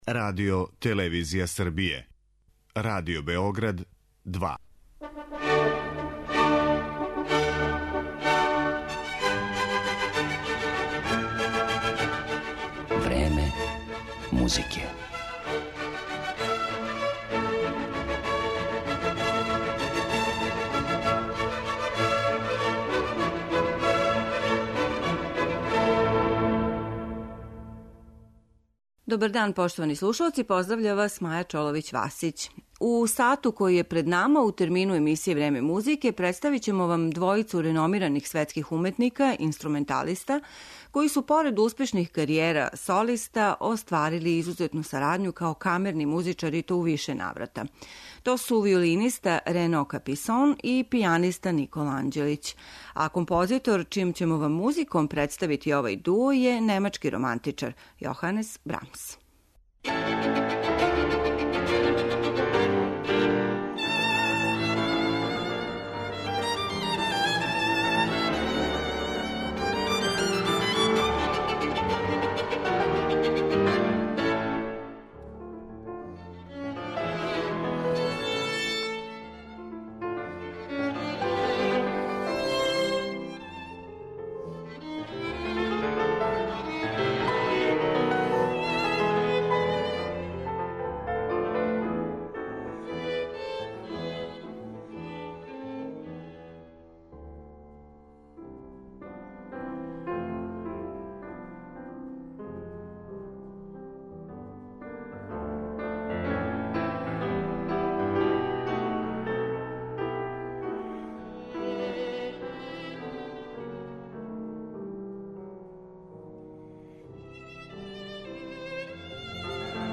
сонате за виолину и клавир